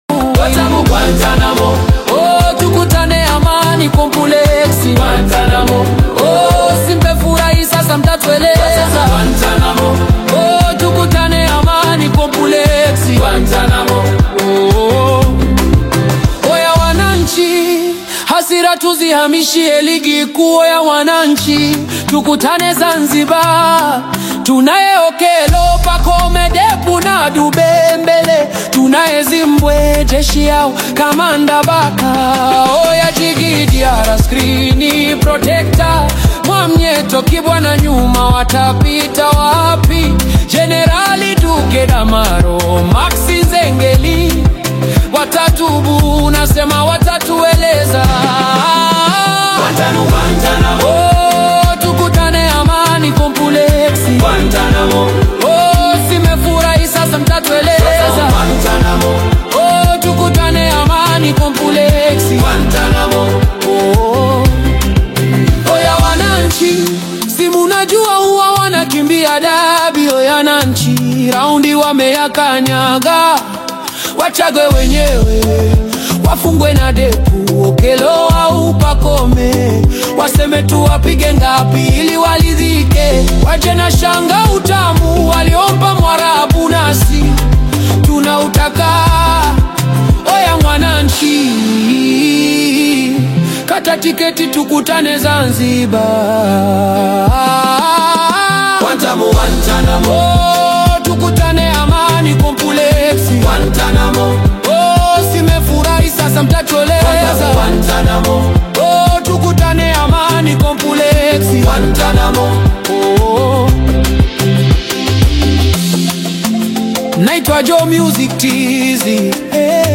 energetic Bongo Flava/Afro-Fusion single
dynamic vocals over vibrant production